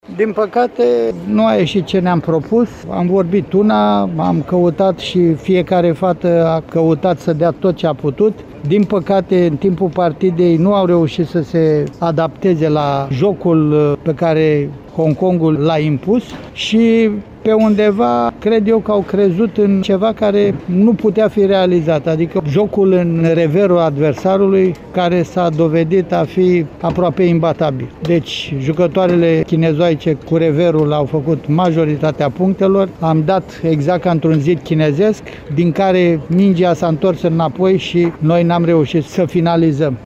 Concluziile ”la cald”, după întâlnirea cu asiaticele,